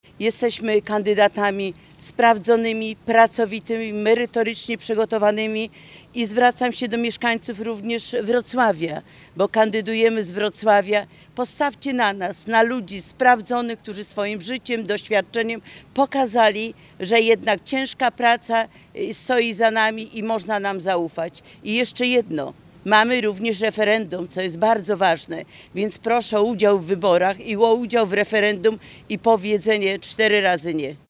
Na Placu Gołębim we Wrocławiu zgromadzili się senatorowie i posłowie, a także osoby ubiegające się o mandat do parlamentu.